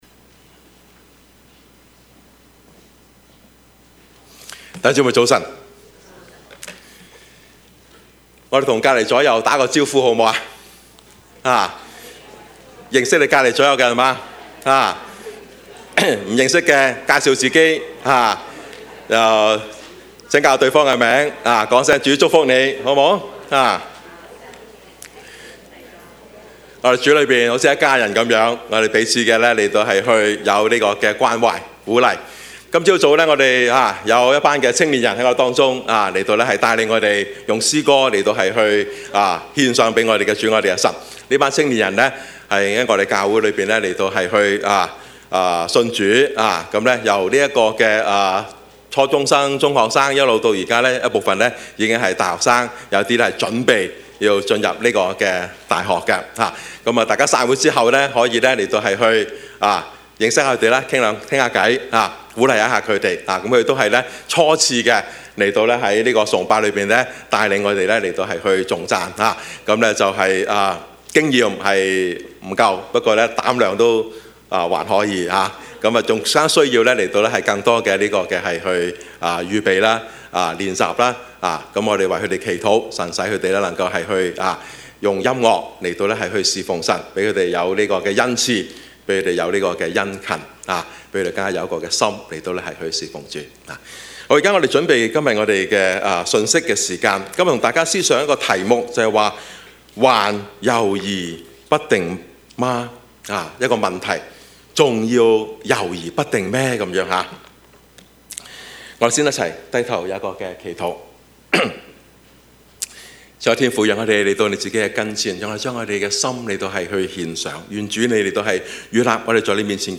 Service Type: 主日崇拜
Topics: 主日證道 « 不要怕, 只要信 天賜我分必要用 »